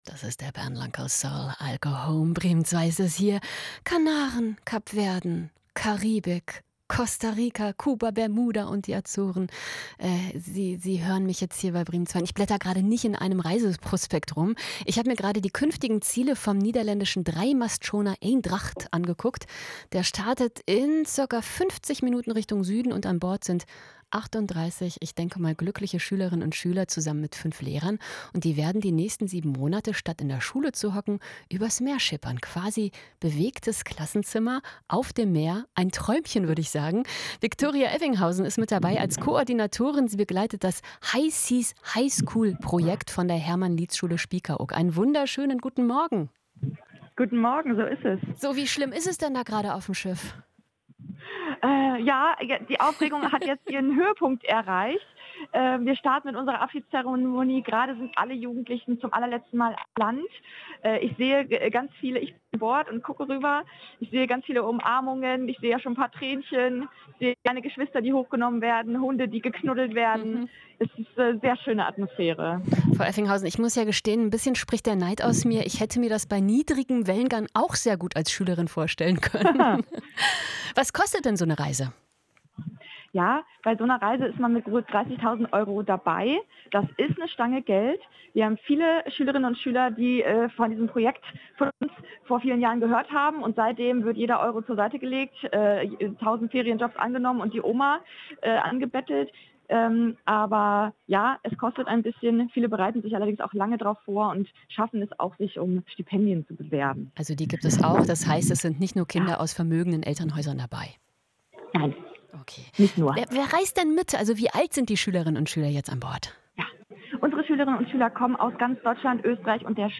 Live-Interview.